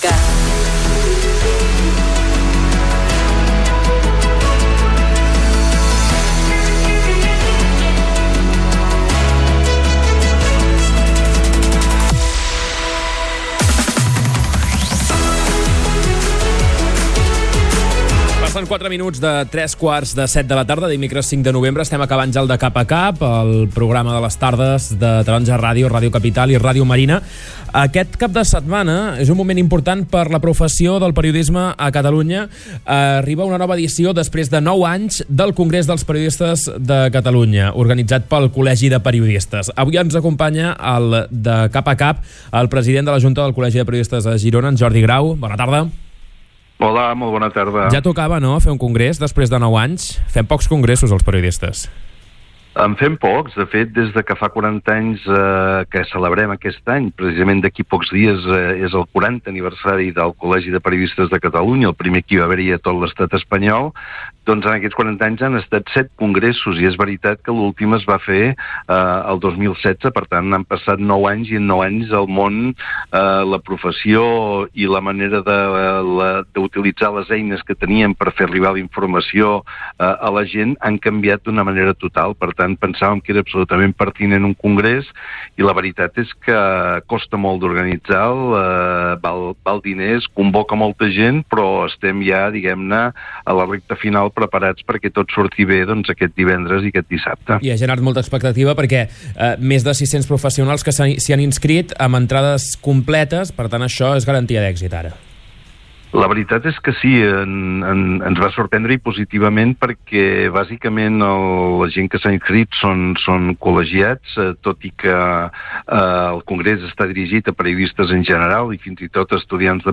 En una entrevista al programa De cap a cap